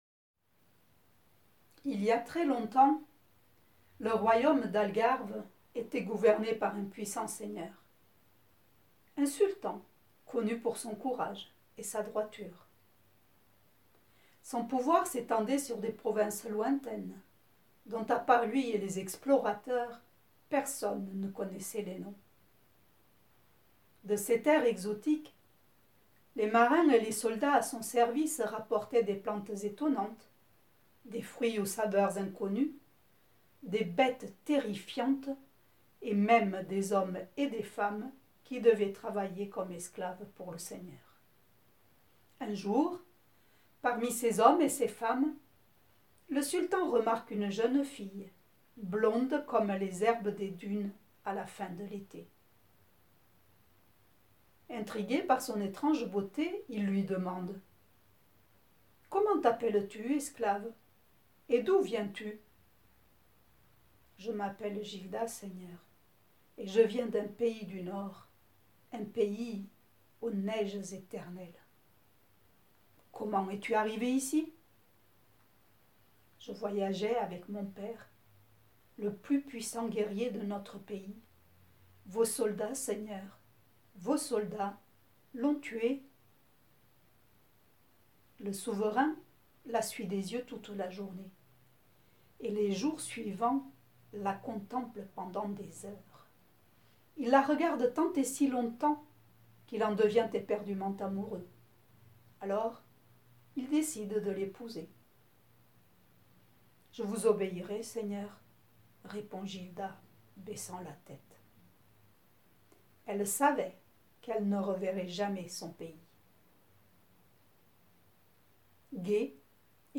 CONTEUSE